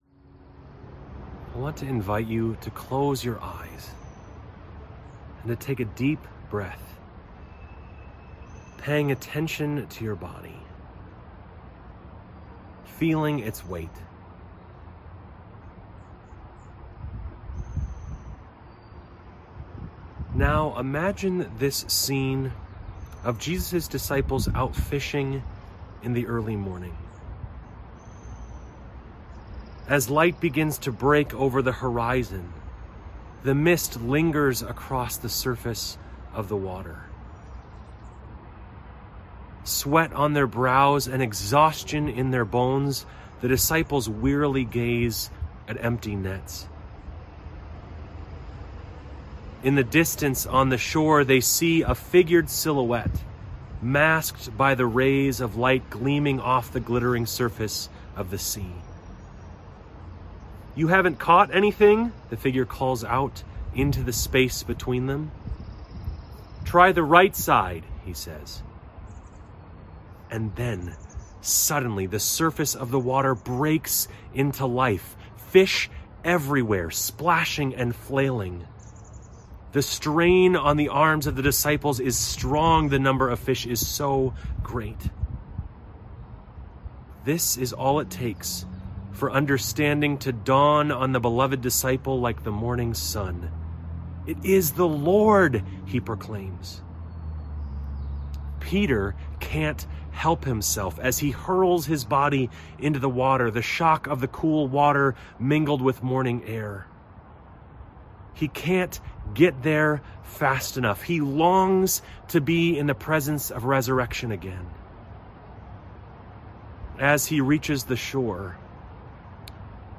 Related Topics: Easter, Resurrection